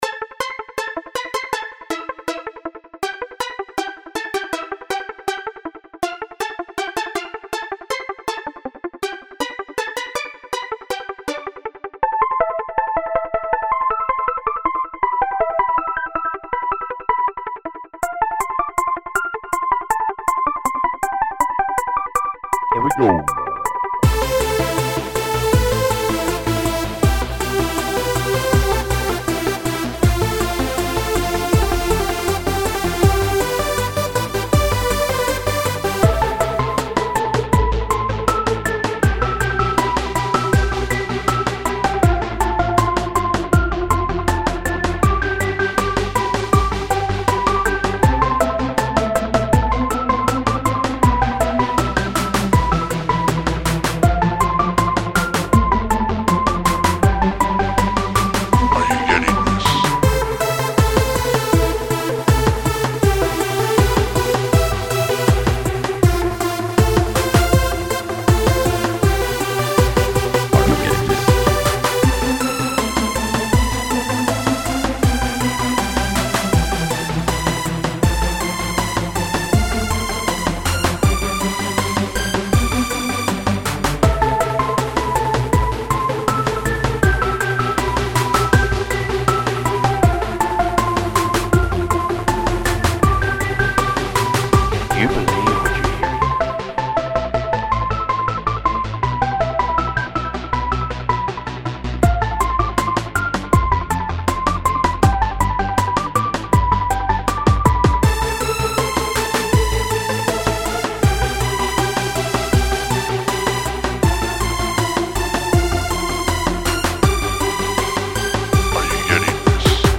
This is the second song I have edited my voice and added it.